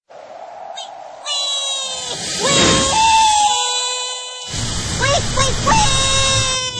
Cartoon Weeee Sound Effect Free Download
Cartoon Weeee